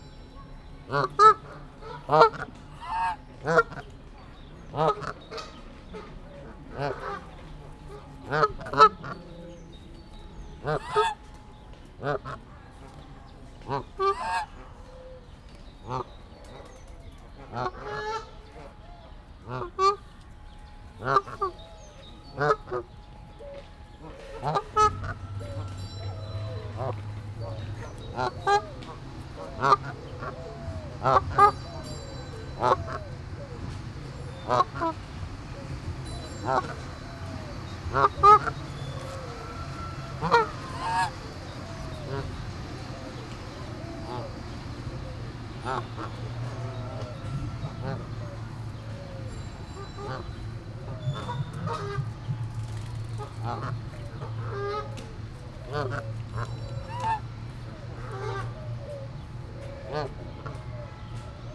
Directory Listing of //allathangok/debrecenizoo2019_professzionalis/kanadai_lud/
egykedvuen0101.WAV